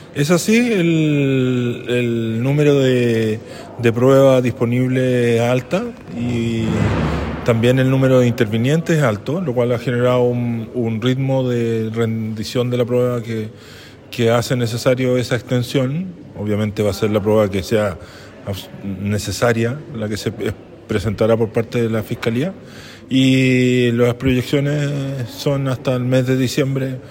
El fiscal del Ministerio Público de Aysén, Luis González, quien encabeza el equipo del ente persecutor que está sosteniendo la acusación, precisó que efectivamente la prueba rendida ha pasado mas lento de lo que se proyectó originalmente.